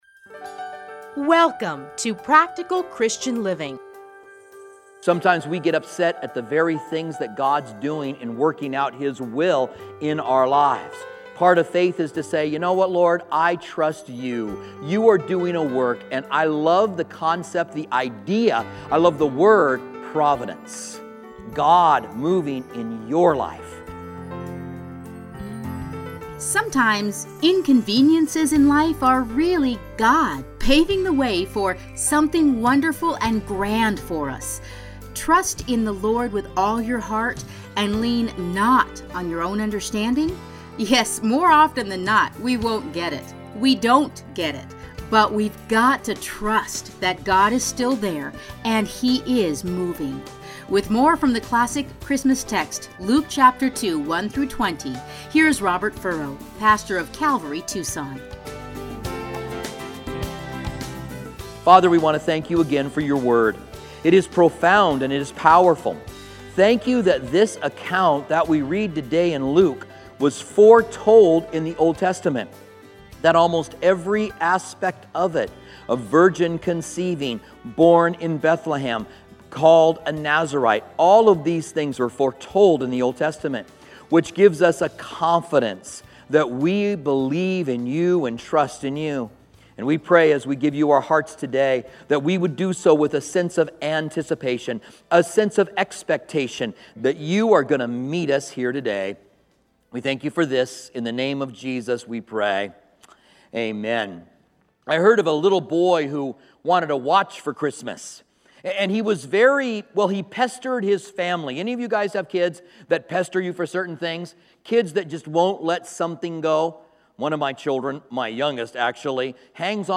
Listen here to a special Christmas message.